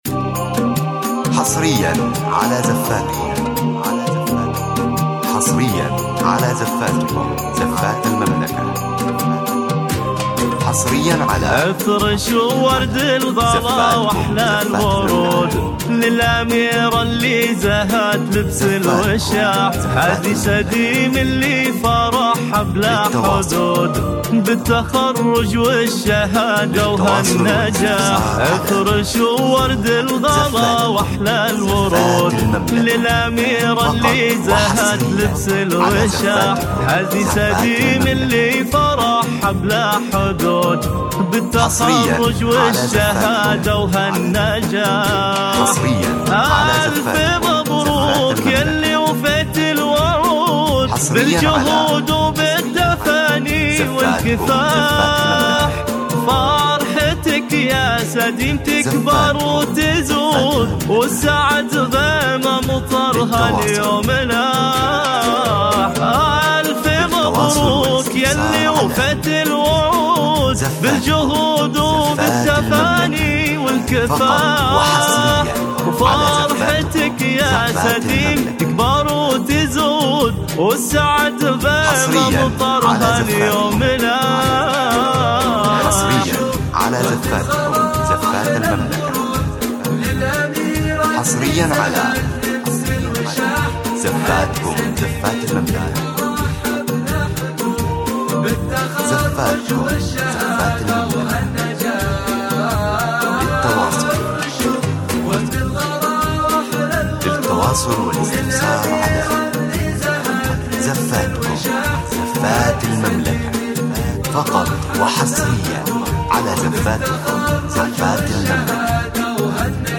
زفة تخرج فخمة بدون موسيقى